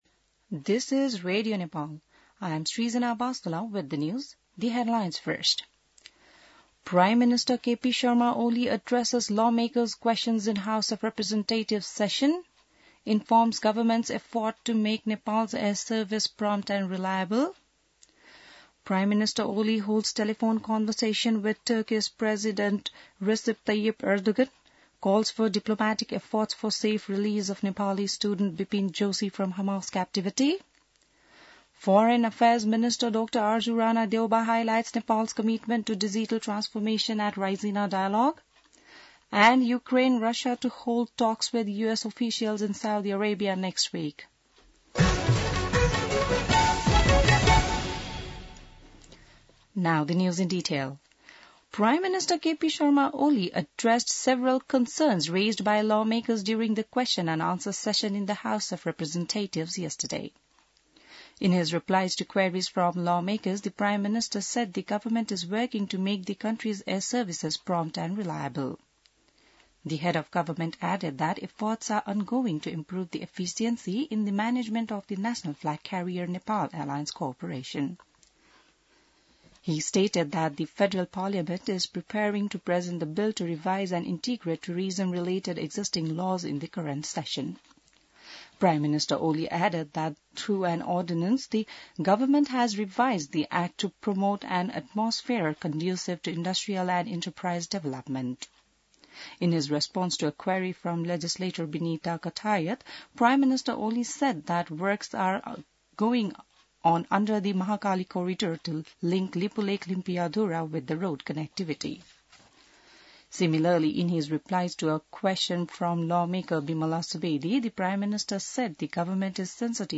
बिहान ८ बजेको अङ्ग्रेजी समाचार : ८ चैत , २०८१